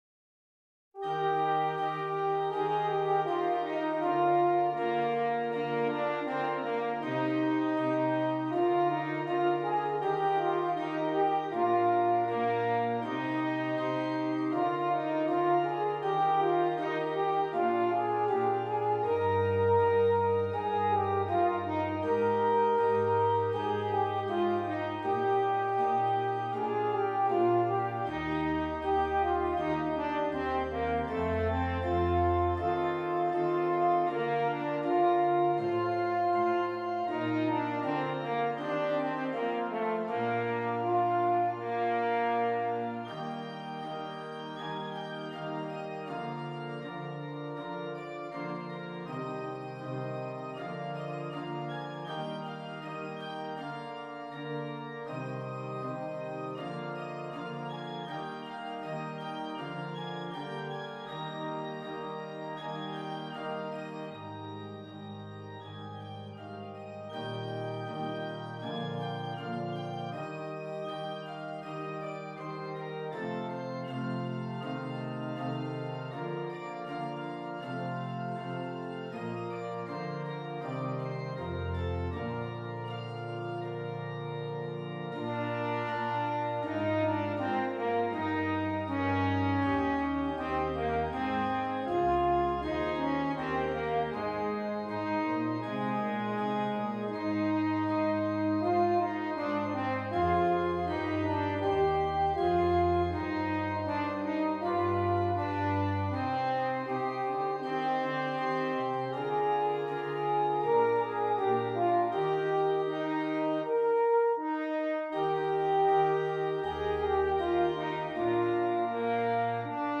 F Horn and Keyboard